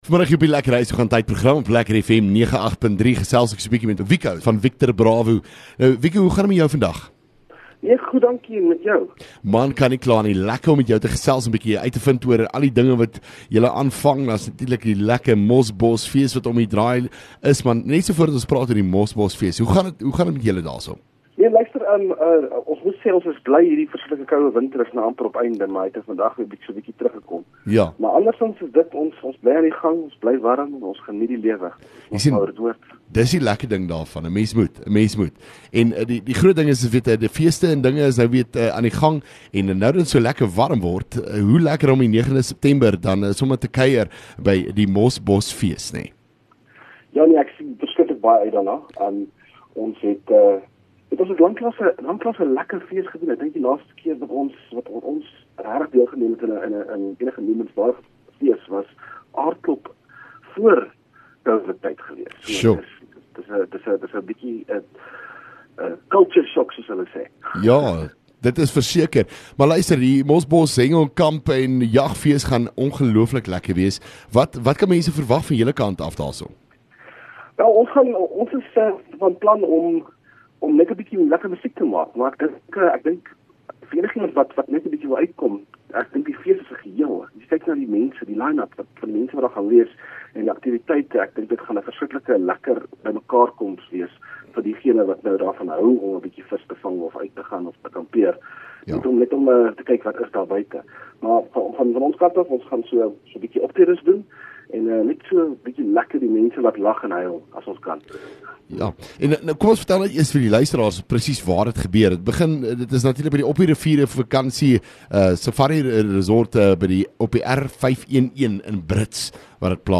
LEKKER FM | Onderhoude 29 Aug Mosbos Fees